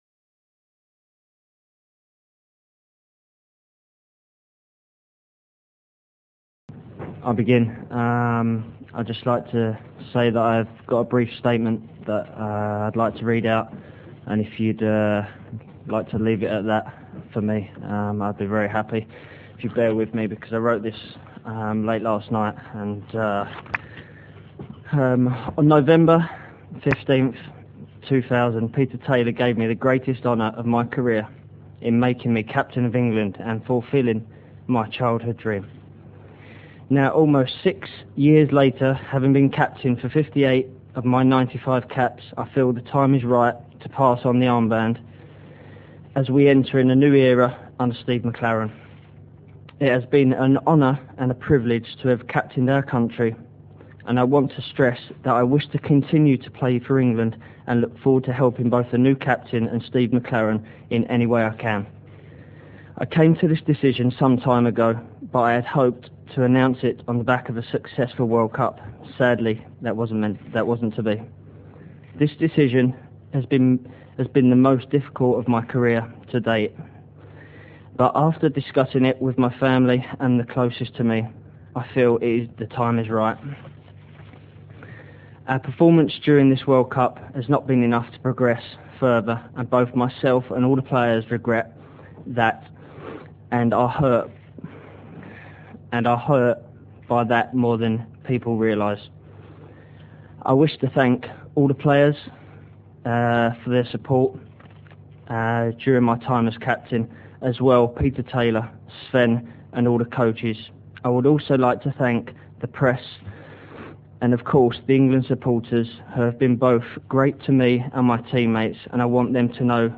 Ses interviews à la Radio étaient d'une profondeur de pensée inégalée !